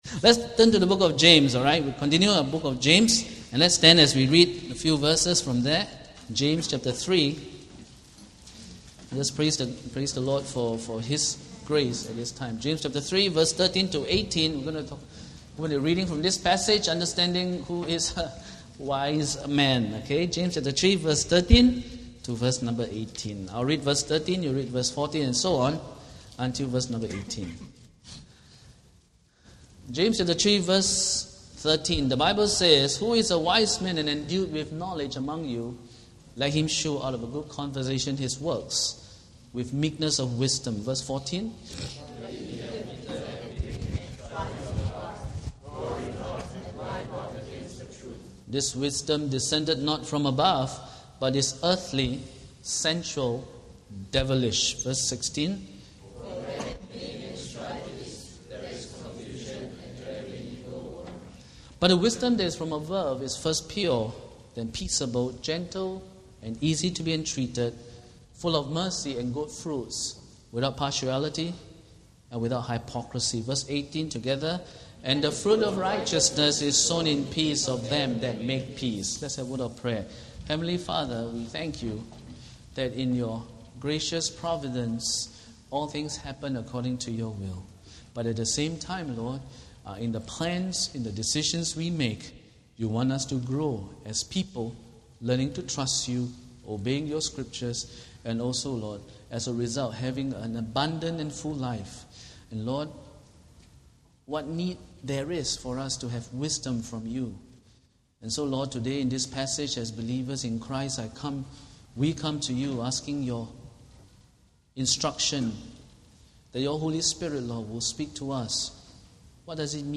Sunday Worship Service